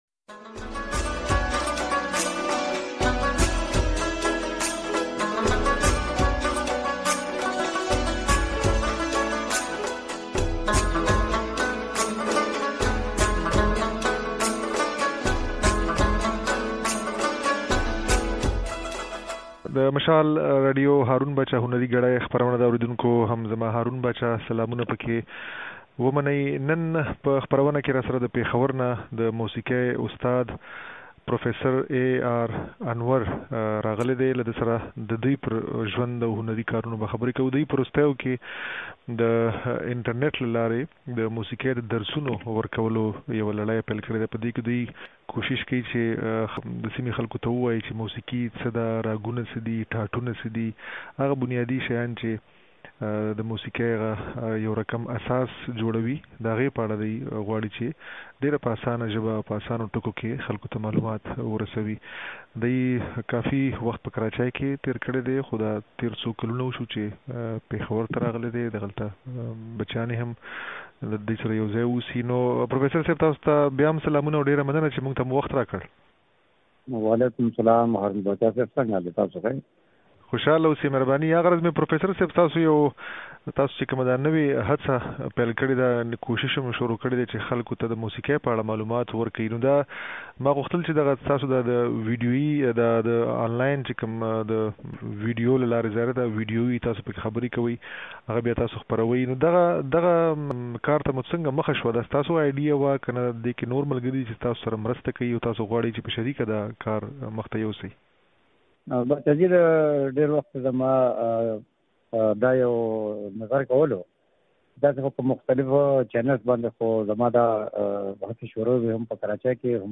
خبرې او سندرې اورېدای شئ